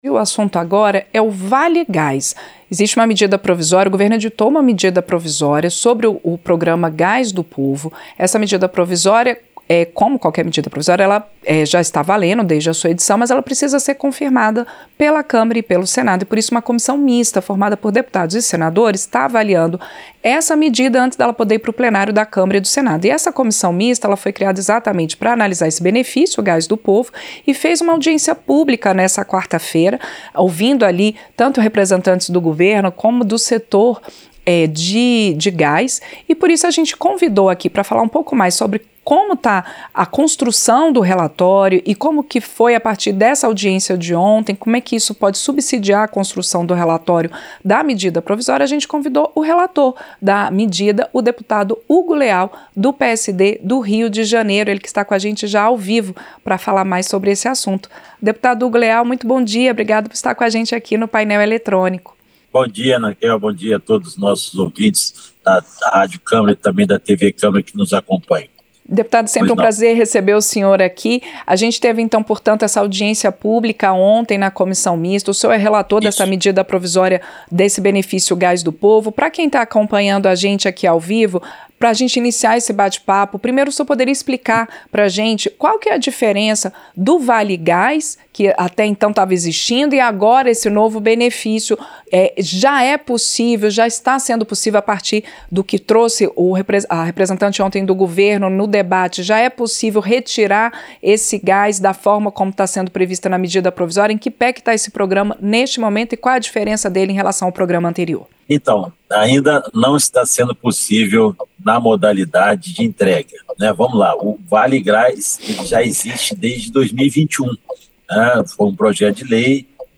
Entrevista - Dep. Hugo Leal (PSD-RJ)